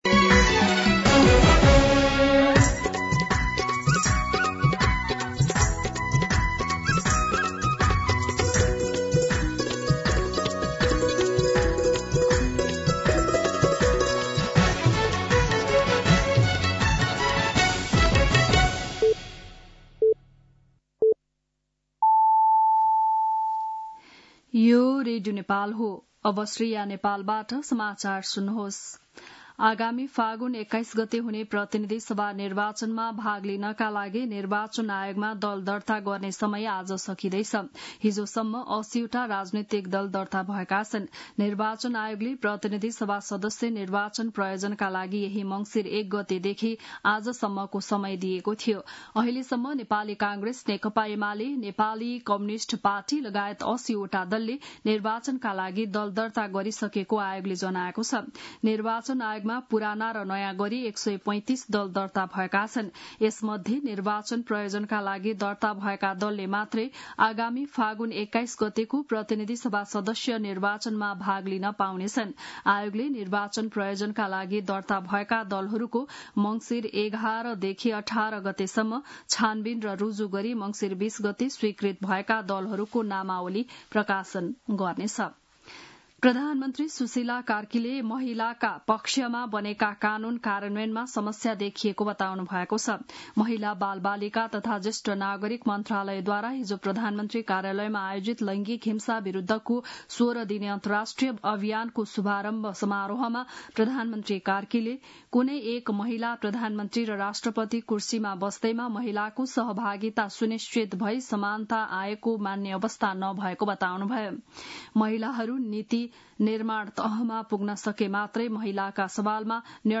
बिहान ११ बजेको नेपाली समाचार : १० मंसिर , २०८२
11am-News-10.mp3